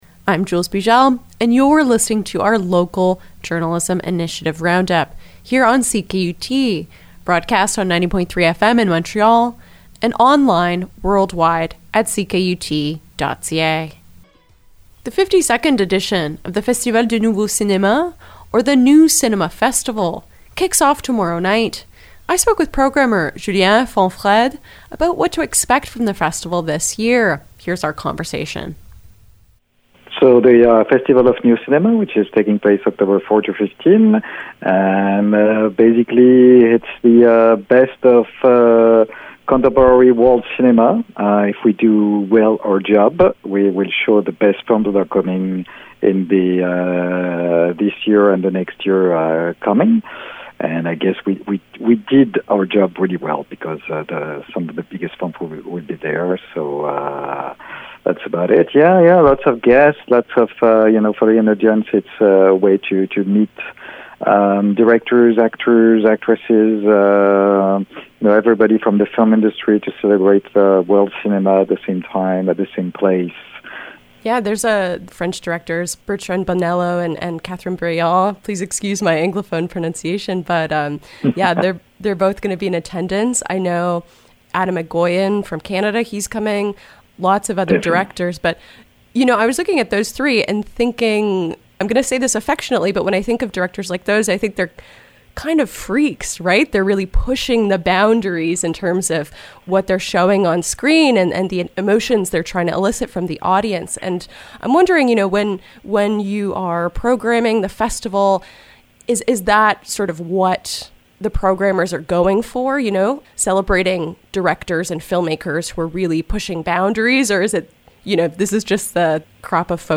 FNC-interview-10-04.mp3